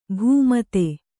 ♪ bhū mate